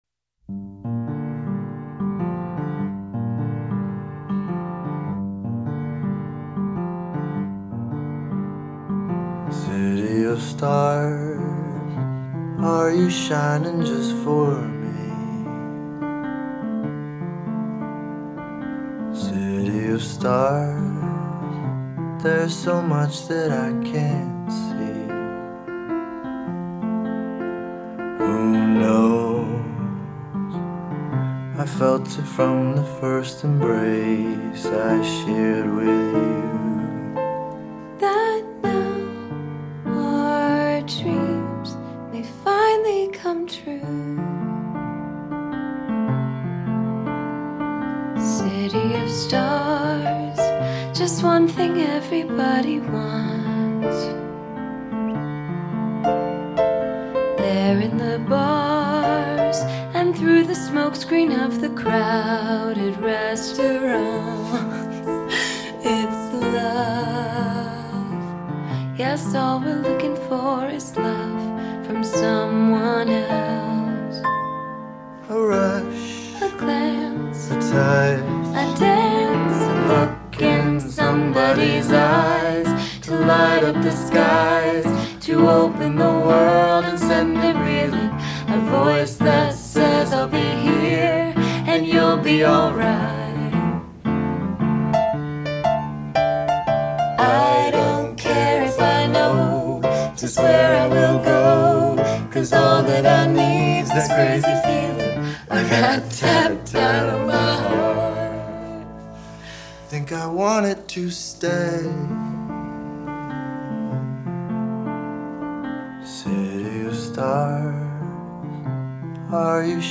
Какой-то унылый мотив. Энергии не чувствуется, не находите?